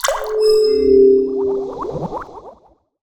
potion_heal_flask_spell_01.wav